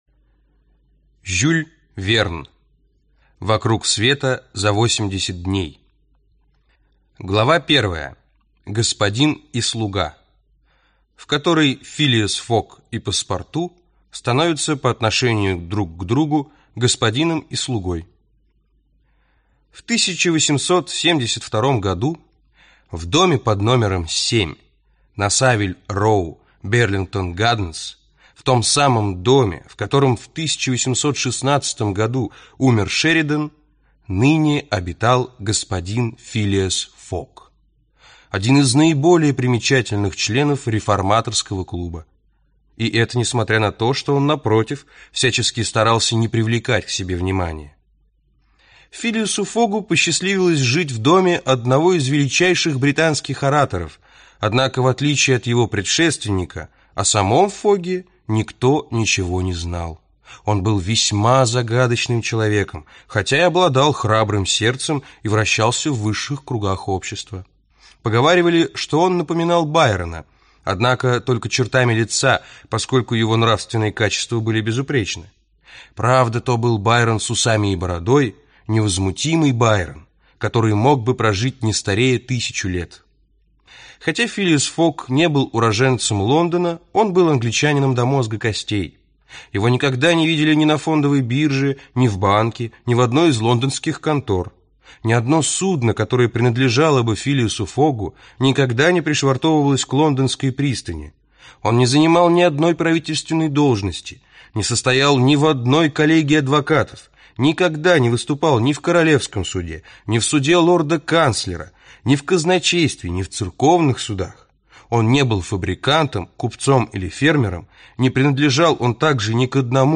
Аудиокнига Вокруг света за 80 дней (сокращенный пересказ) | Библиотека аудиокниг